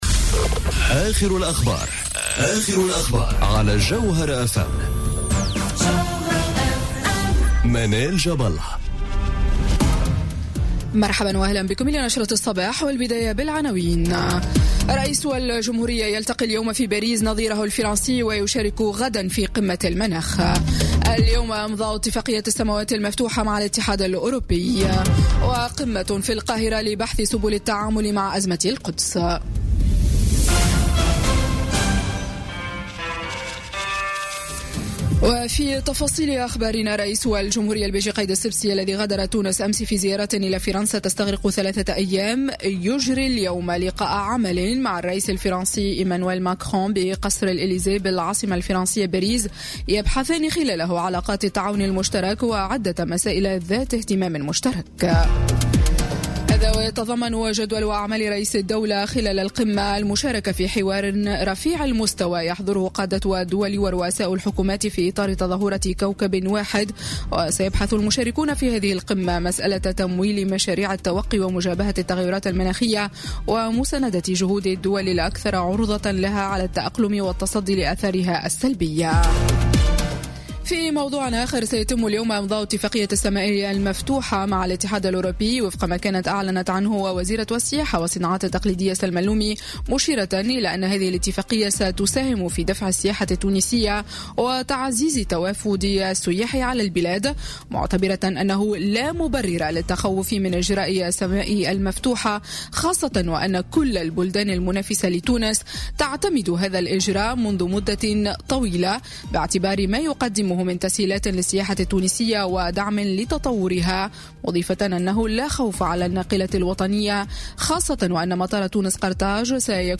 نشرة أخبار السابعة صباحا ليوم الإثنين 11 ديسمبر 2017